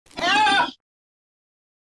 deer scream
Deer scream sound effect meme soundboard clip with loud, panicked animal scream, perfect for chaotic or funny reaction edits.